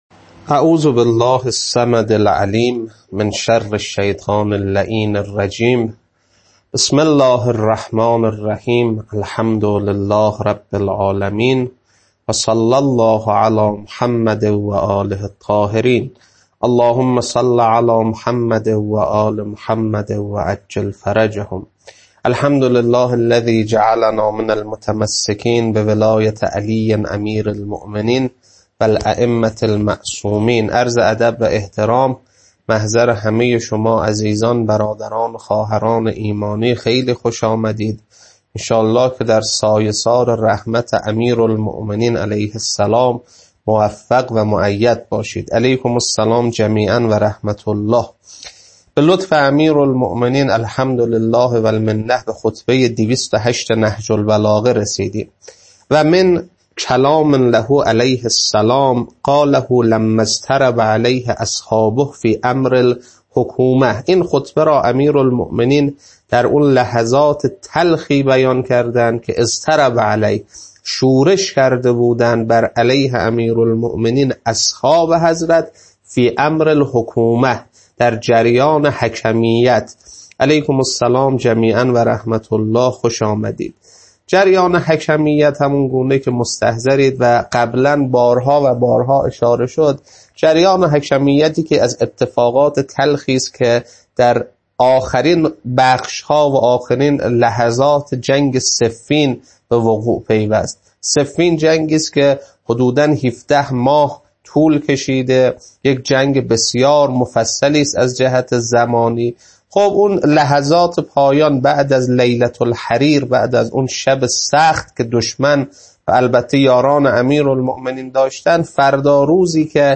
خطبه 208.mp3